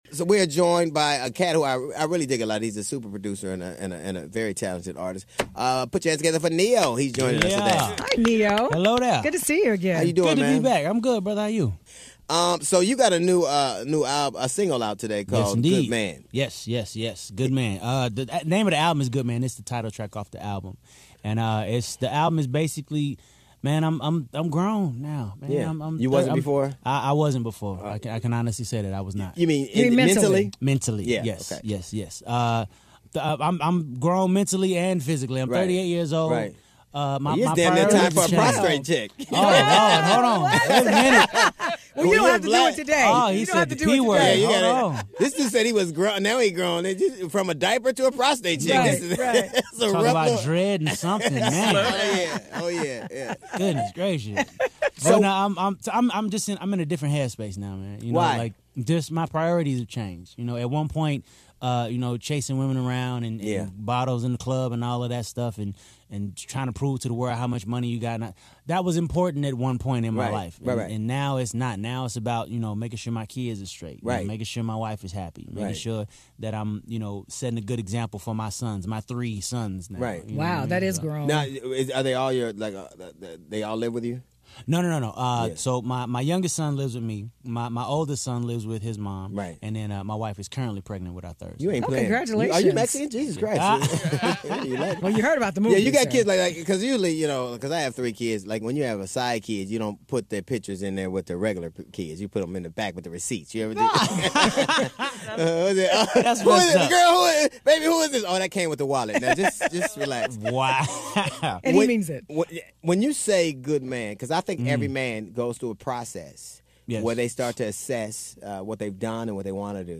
DL Hughley Show Interviews